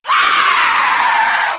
witch_dies.ogg